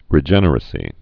(rĭ-jĕnər-ə-sē)